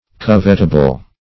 Covetable \Cov"et*a*ble\ (k?v"?t-?-b'l), a. That may be coveted; desirable.
covetable.mp3